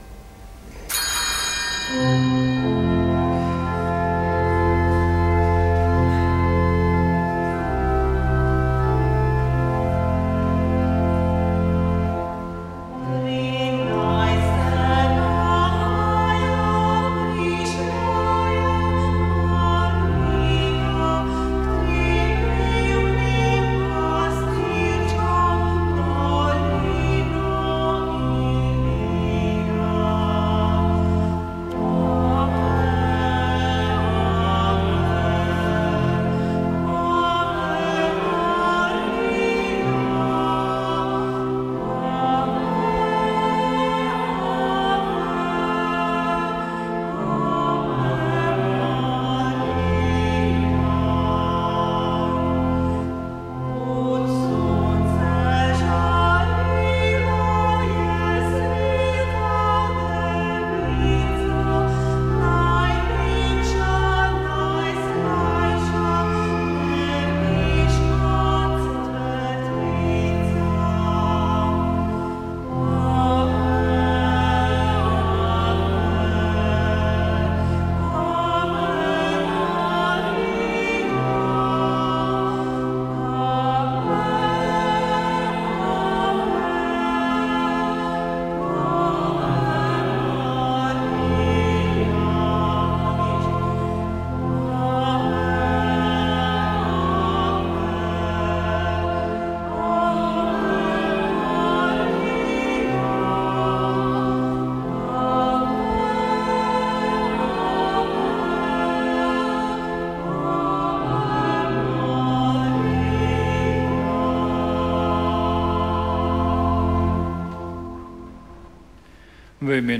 Sv. maša iz cerkve Marijinega oznanjenja na Tromostovju v Ljubljani 18. 3.